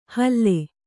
♪ halle